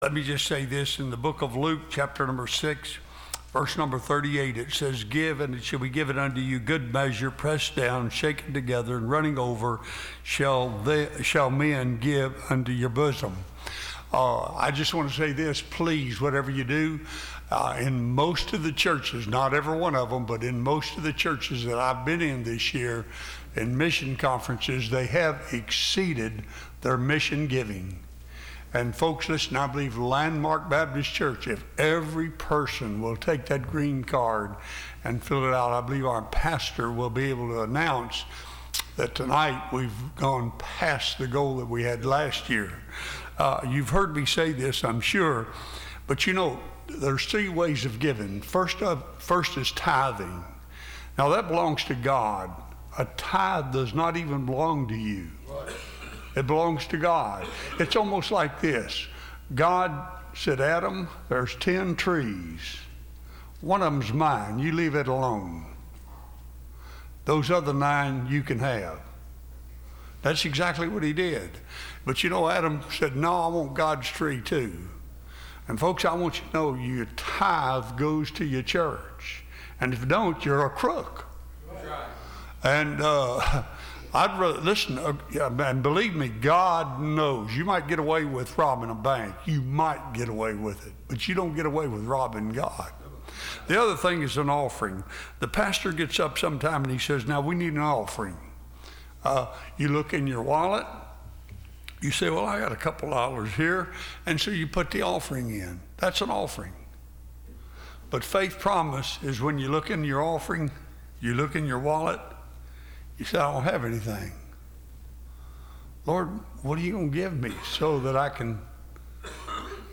Testimonies – Landmark Baptist Church
Service Type: Sunday School